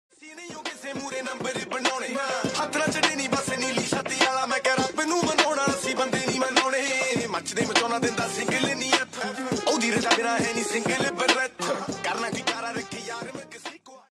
6TypeRelaxing / Soothing Instrumental Tone